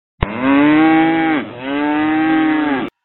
牛 | 健康成长
niujiao.mp3